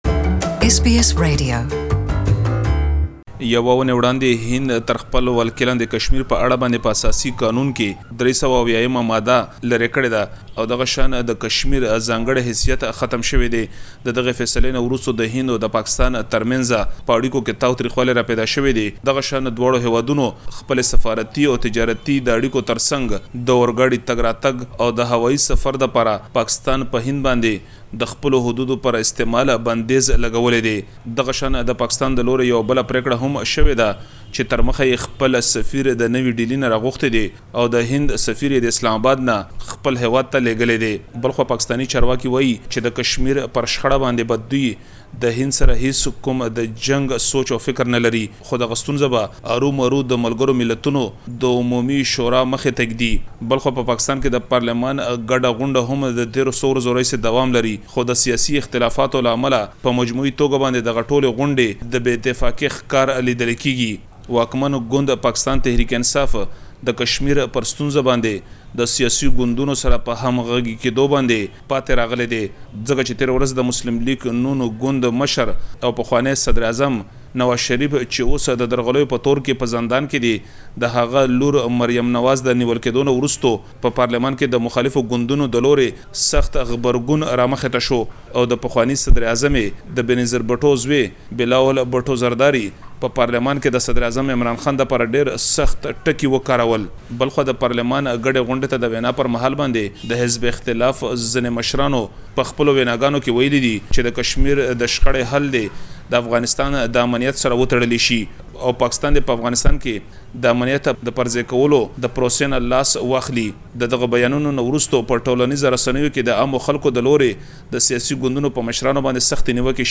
Please listen to the full report in Pashto language.